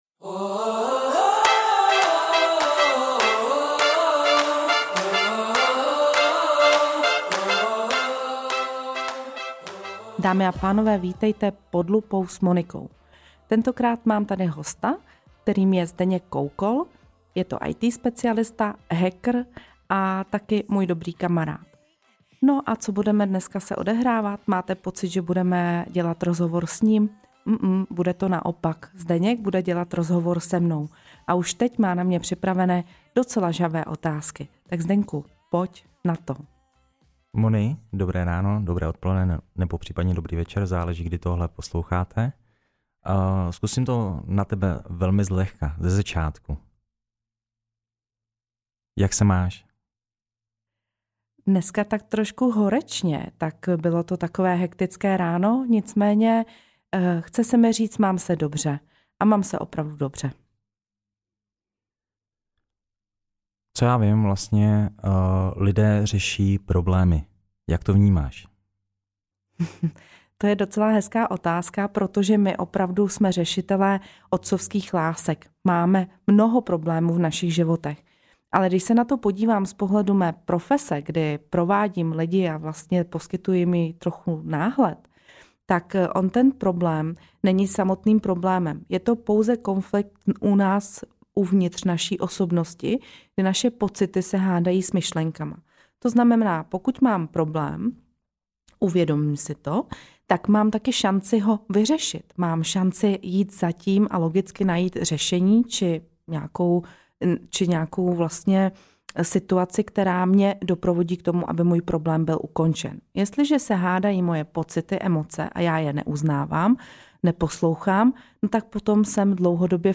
Rozhovor o vztazích k lidem i penězům, přizpůsobování se, chození k terapeutovi i o potřebě někam patřit.